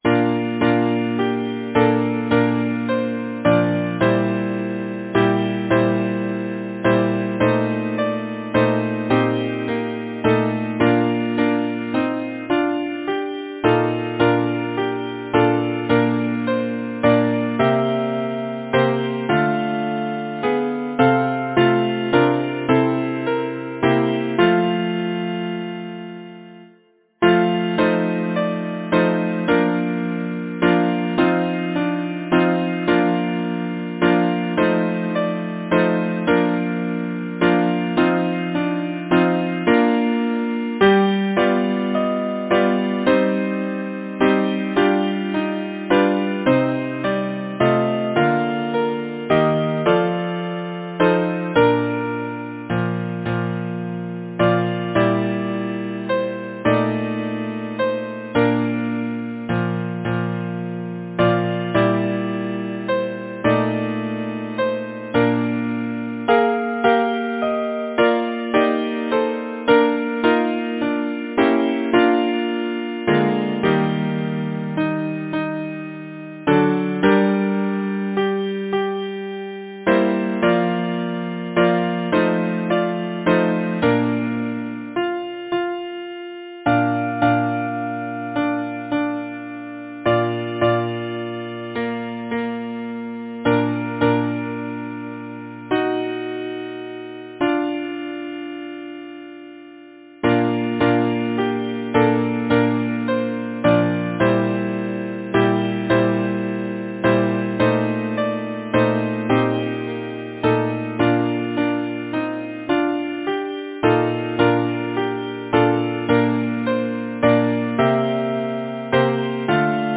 Title: Winter song Composer: William James Robjohn Lyricist: Number of voices: 4vv Voicing: SATB Genre: Secular, Partsong
Language: English Instruments: A cappella